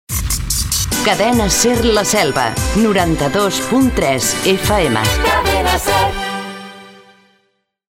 Identificació i freqüència de l'emissora
FM